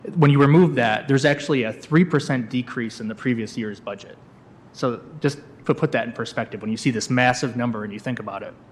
Commissioner Chris Preadel says these are once-in-a-generation funds that have been made available by the state and federal governments for local improvements.